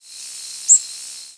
"Lawrence's Warbler" diurnal flight calls
Diurnal calling sequence: 1. New Jersey August 19, 1998 (MO) . Male perched, then in short flight.